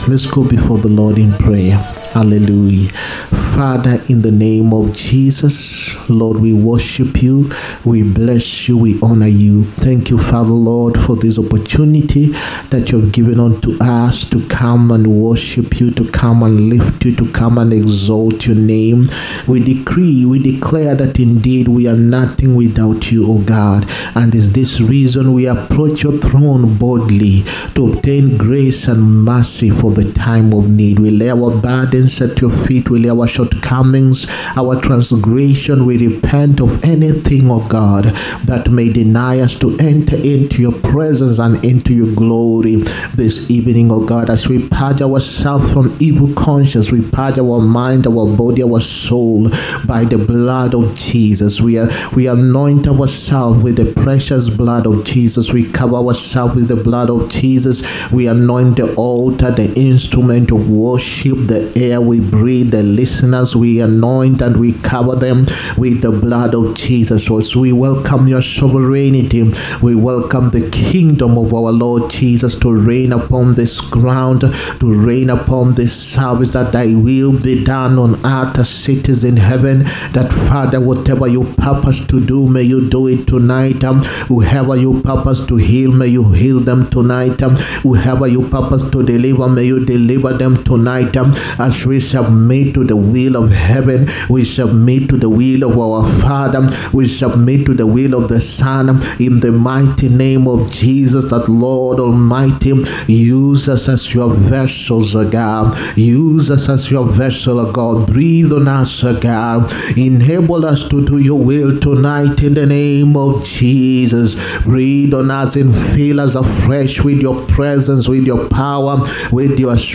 HEALING, PROPHETIC AND DELIVERANCE SERVICE. 15TH MARCH 2025. PART 1.